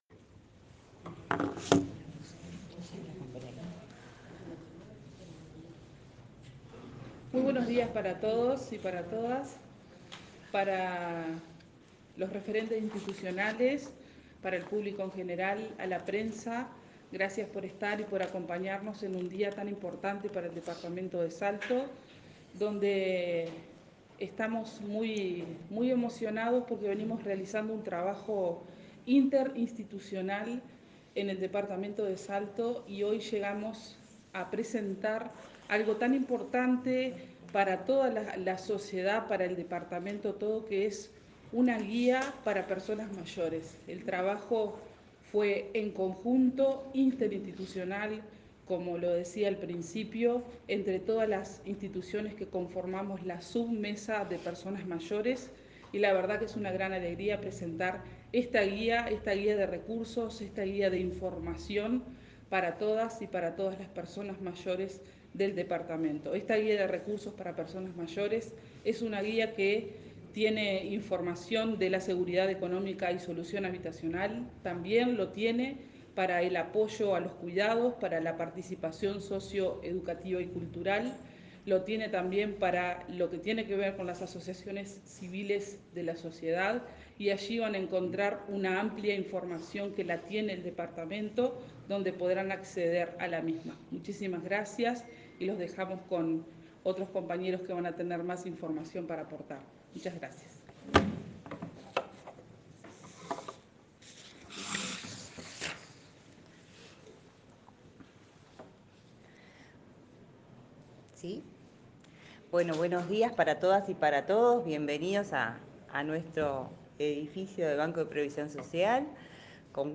Esta mañana en la Sala de Reuniones del Banco de Previsión Social se llevó a cabo la presentación de la Guía de Recursos para personas mayores 2023, la Sub Mesa de Personas Mayores de Salto estuvo presente junto a referentes nacionales y locales.